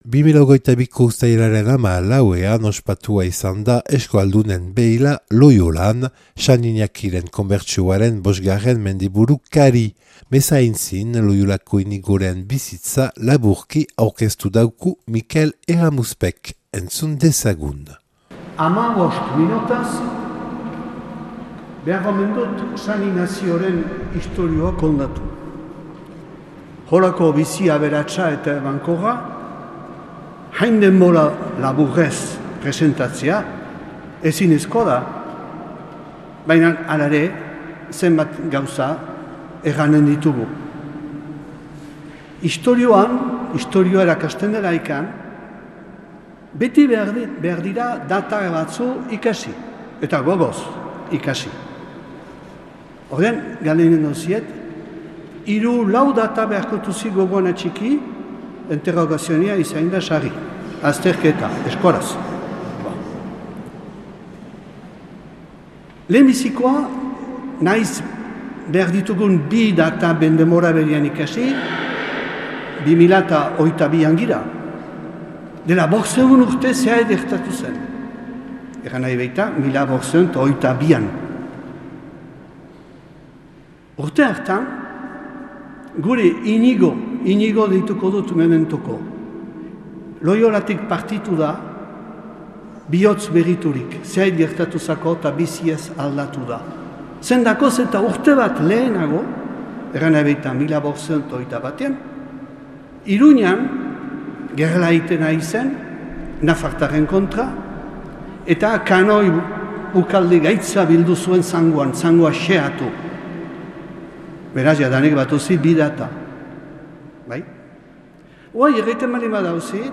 Loiolan grabatua 2022.